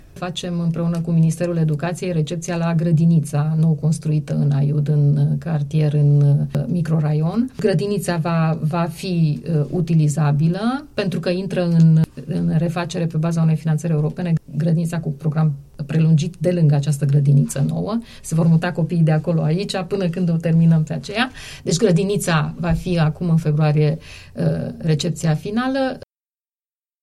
AUDIO | Oana Badea, la Unirea FM: Grădiniță nouă finalizată în municipiul Aiud